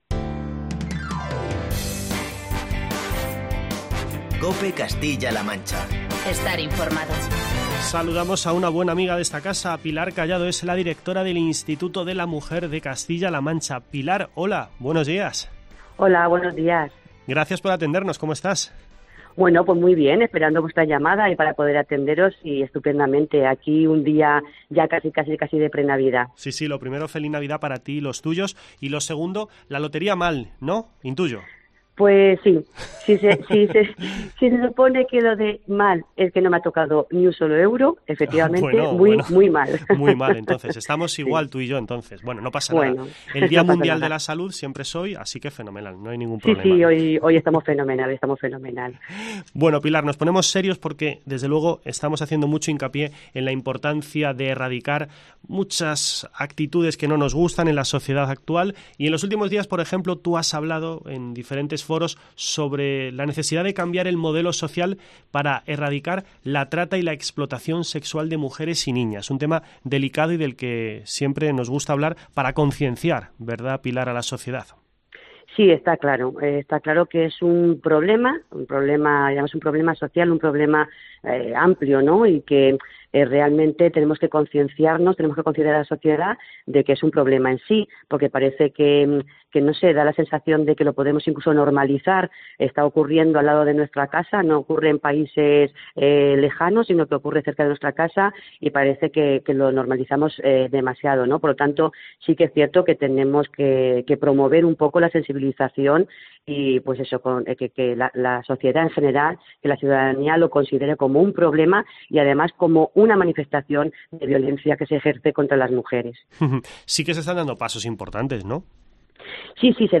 AUDIO: Entrevista con la directora del Instituto de la Mujer de Castilla-La Mancha